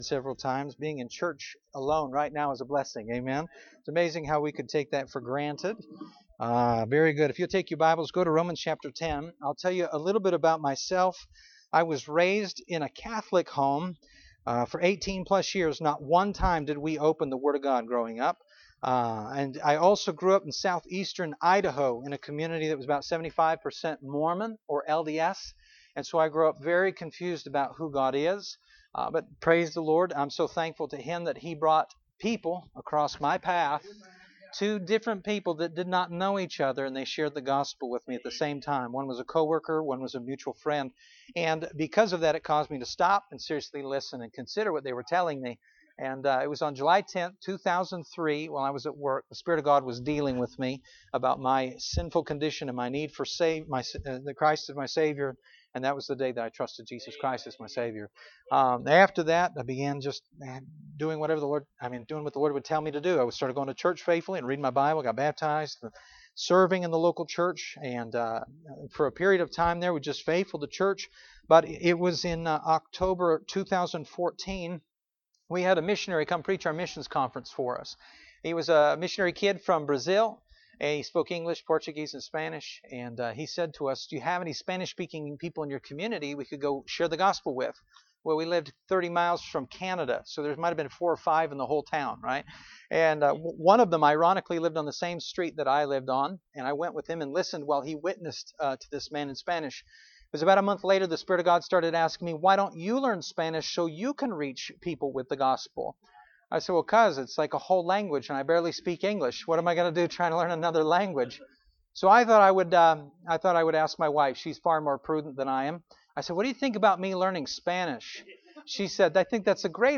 Romans 10:1-15 Service Type: Mission Conference Bible Text